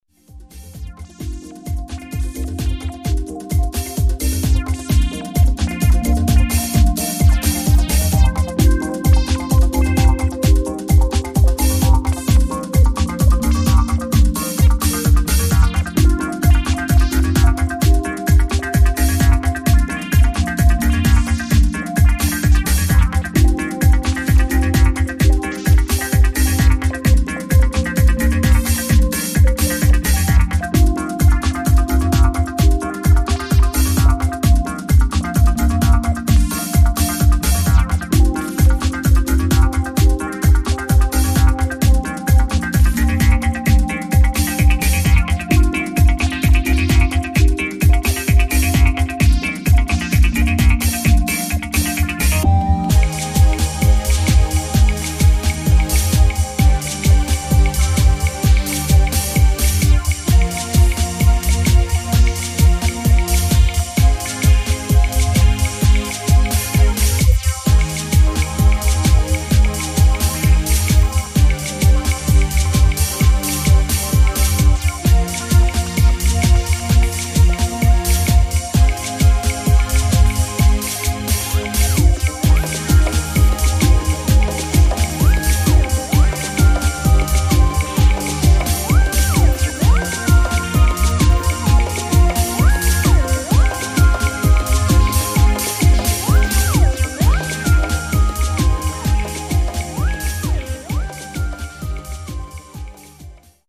[ TECHNO ]